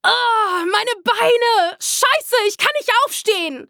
Charakter Soldat:
Stimmalter: ca. 18-35 Jahre